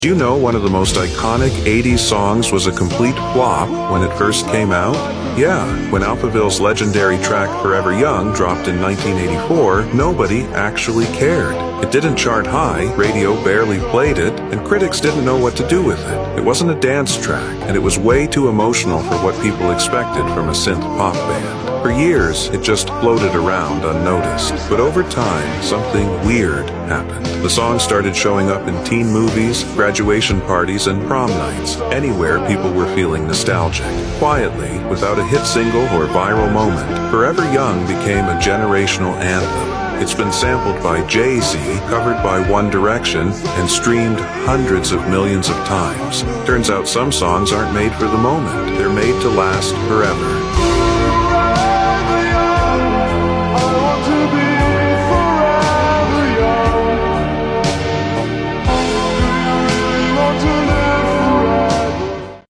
metal/rock edits